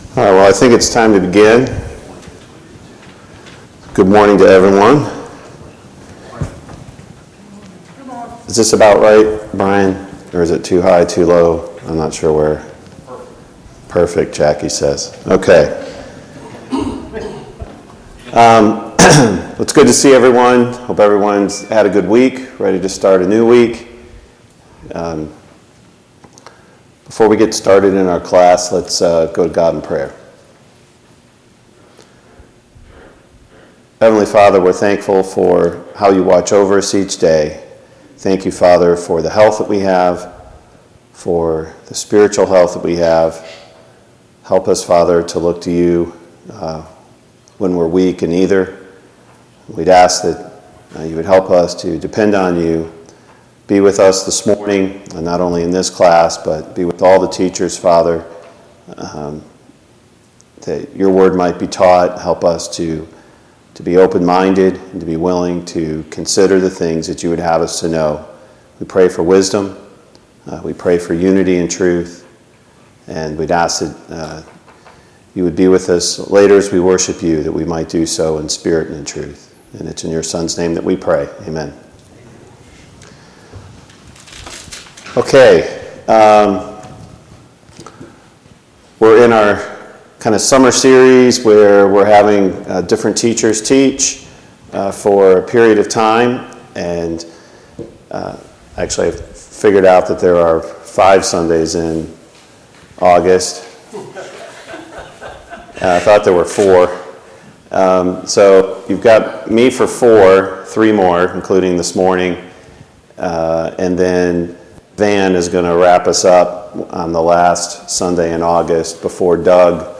Psalm 46:10 Service Type: Sunday Morning Bible Class Topics: Who is God?